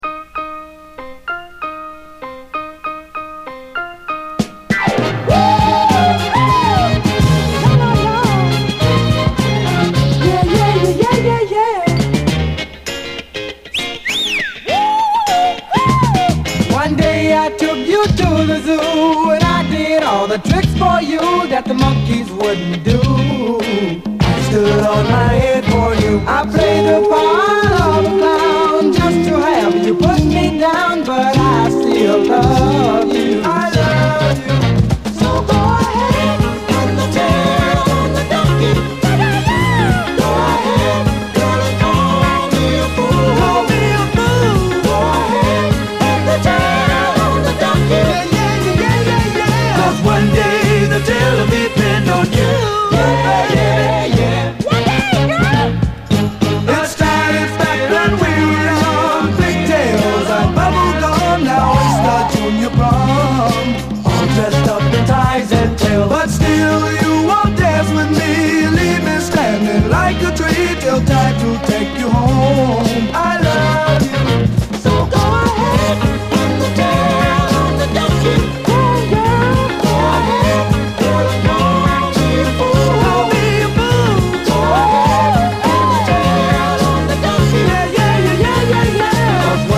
ゴージャス＆ダイナミックなオーケストラが目いっぱいにムードを盛り上げる、感動のドリーミー・ソウル45！
なんという豊かなサウンド！